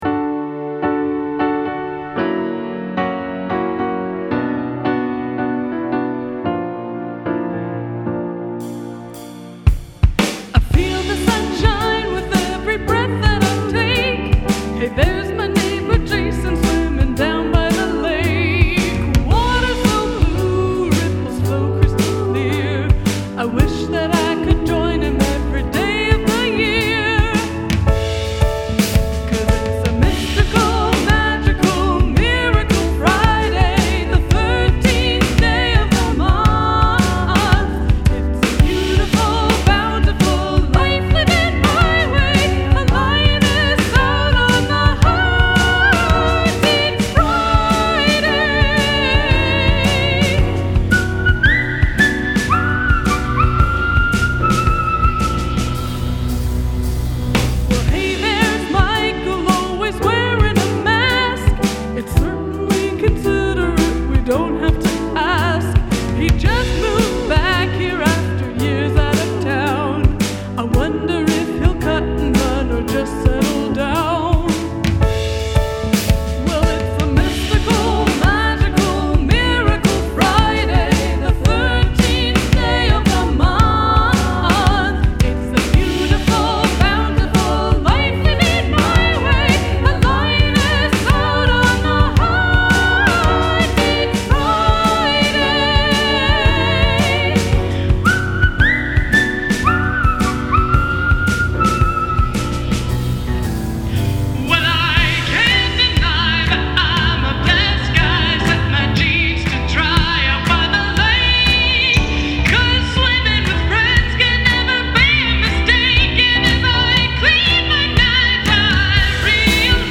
write a feel-good song with happy lyrics and upbeat music